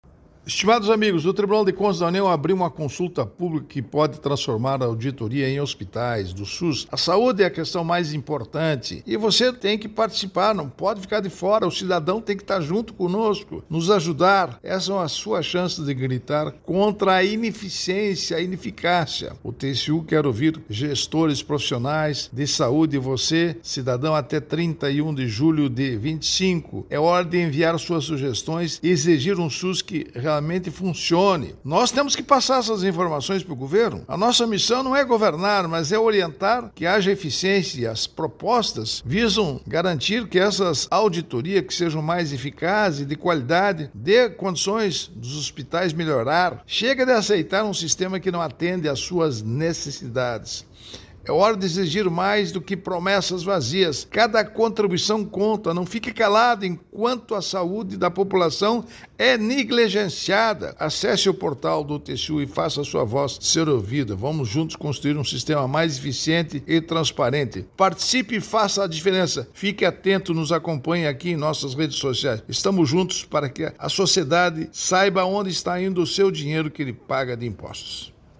Comentário do ministro Augusto Nardes do Tribunal de Contas da União.